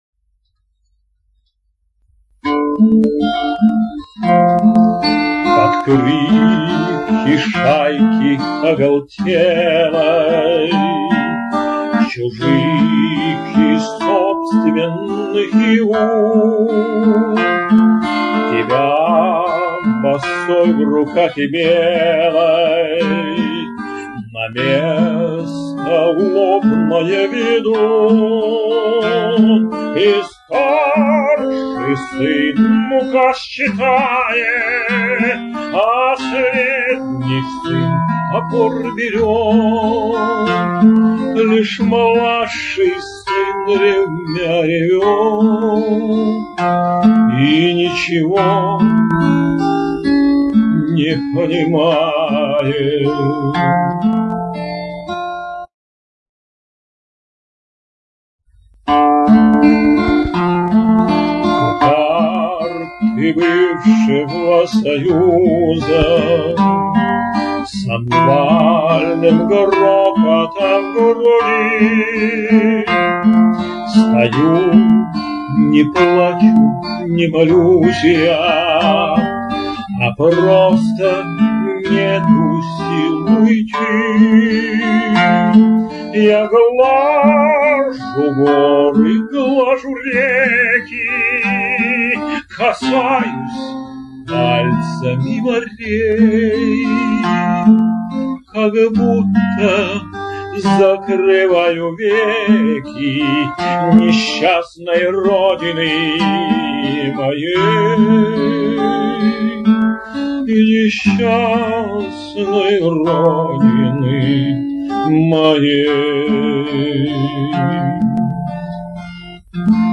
Встретил нового для меня автора - Эдуард Шестунов, композитор, сочиняет романсы.
Правда, качество не очень хорошее.